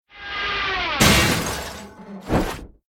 KART_Gag_Hit_Anvil.ogg